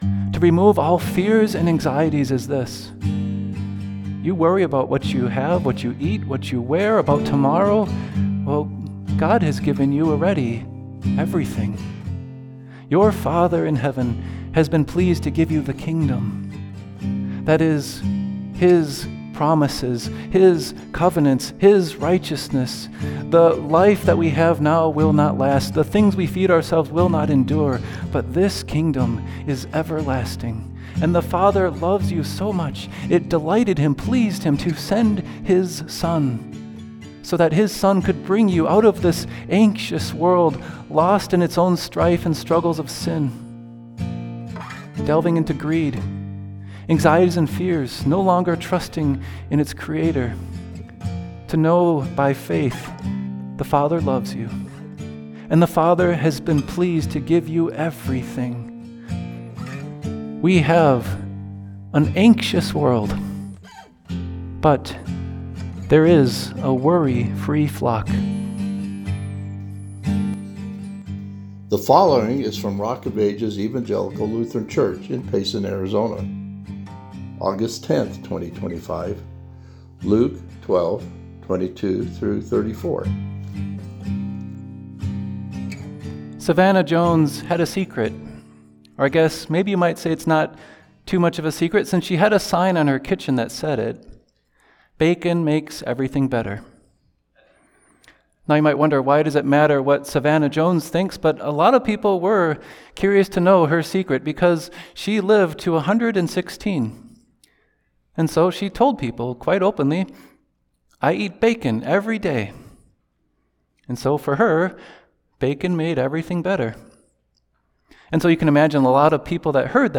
Luke 12:22-34 ● 2025-08-10 ● Listen to Sermon Audio ● Watch Video